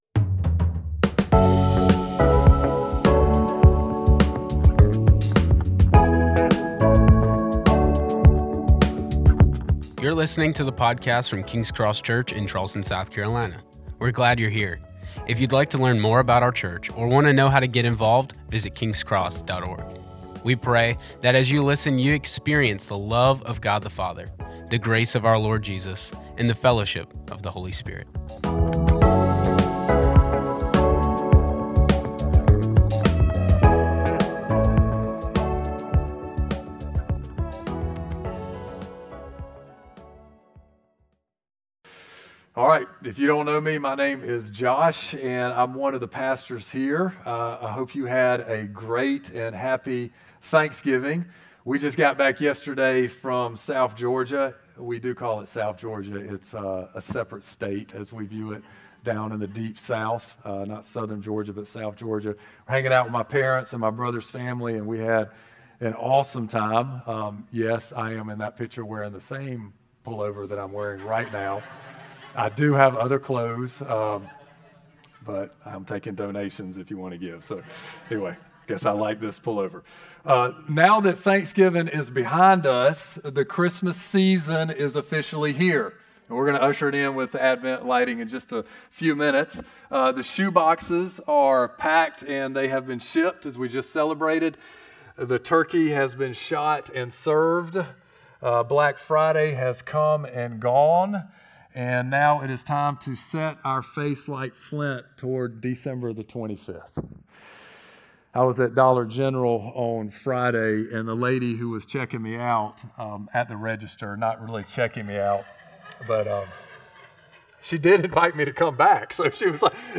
A message from the series "In Those Days."